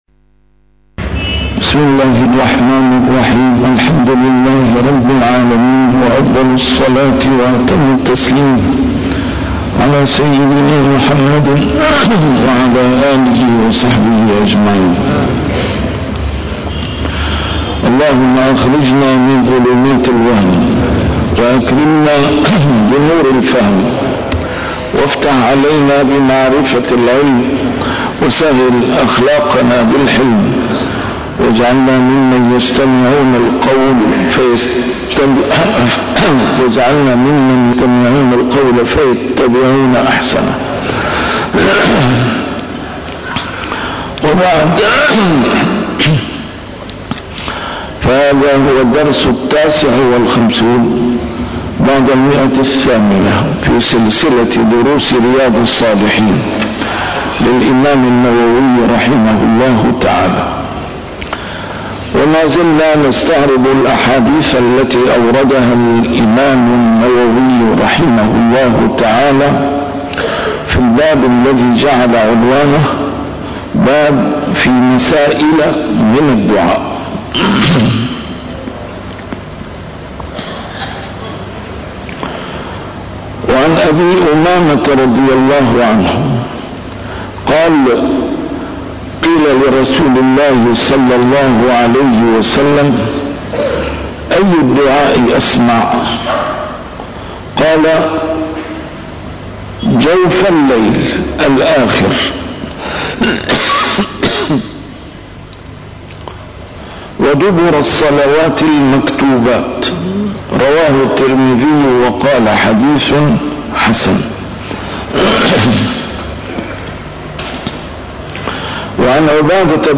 شرح كتاب رياض الصالحين - A MARTYR SCHOLAR: IMAM MUHAMMAD SAEED RAMADAN AL-BOUTI - الدروس العلمية - علوم الحديث الشريف - 859- شرح رياض الصالحين: في مسائل من الدعاء